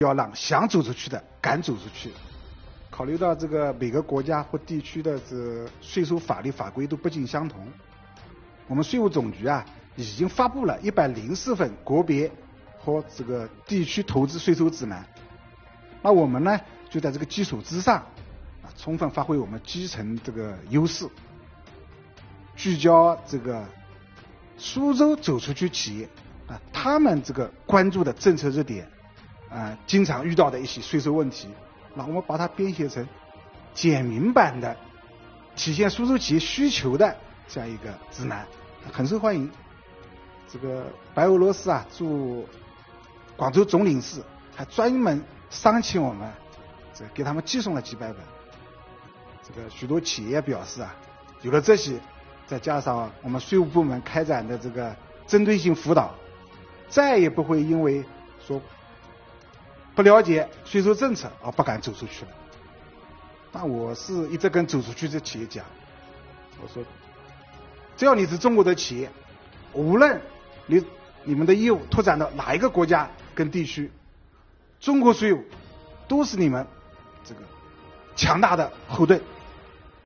7月15日，中共中央宣传部举行中外记者见面会，邀请5名税务系统党员代表围绕“坚守初心 税收为民”主题与中外记者见面交流。